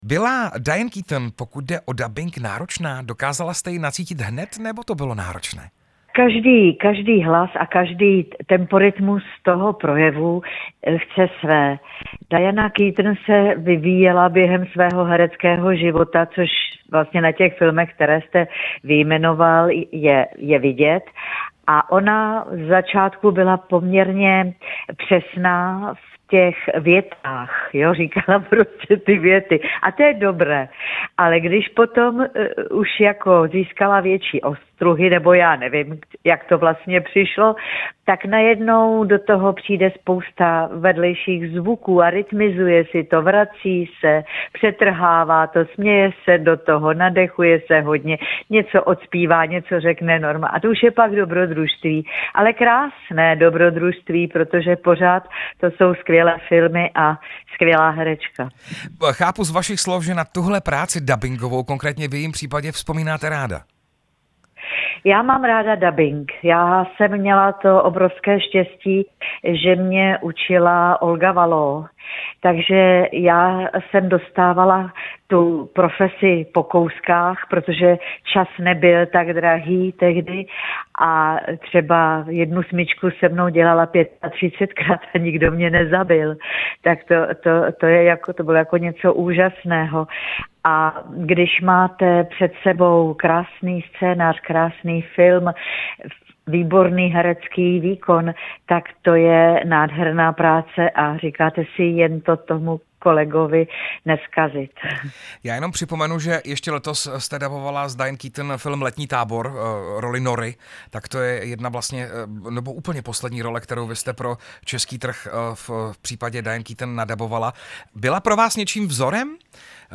Rozhovor s herečkou Milenou Steinmasslovou